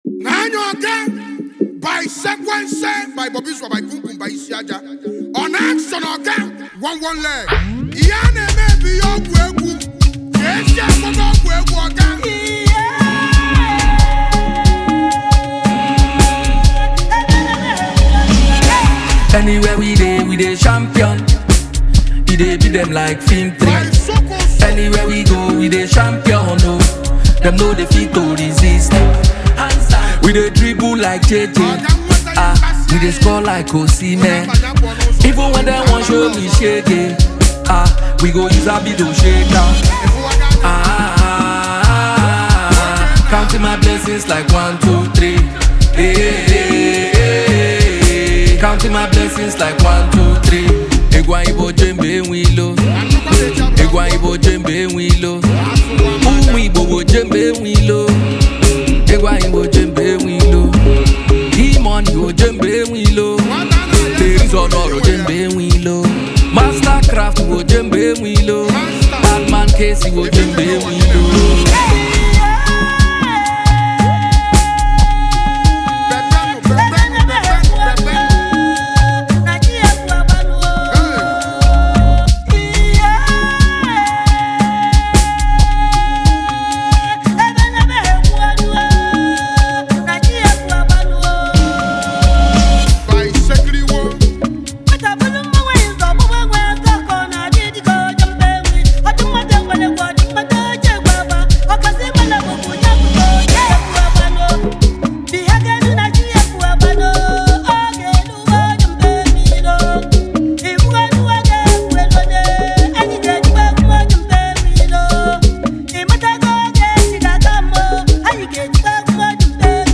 highlife track